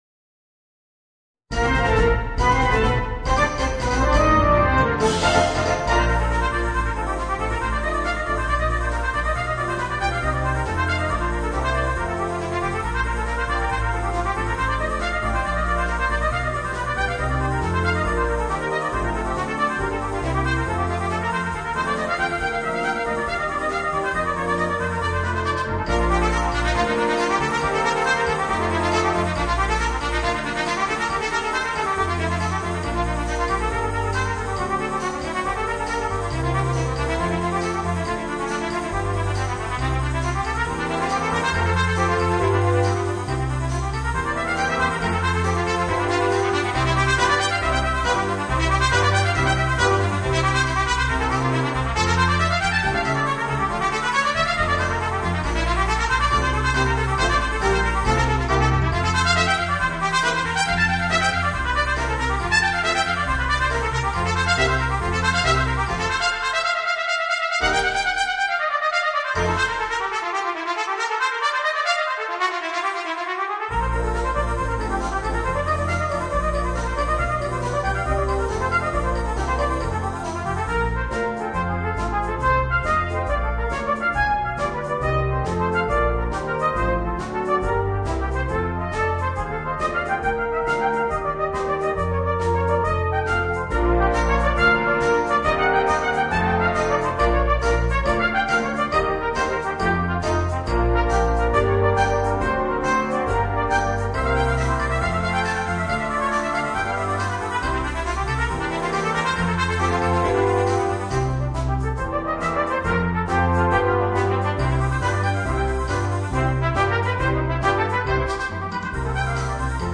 Voicing: 2 Cornets and Brass Band